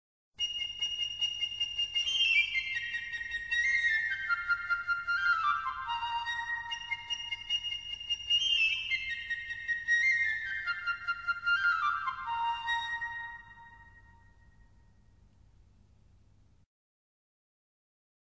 09 Flautín.mp3